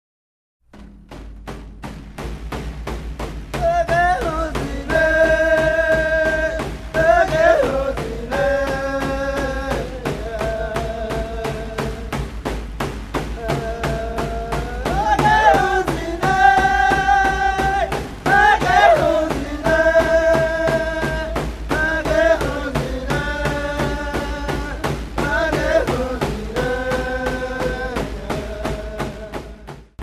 traditional North American Indian music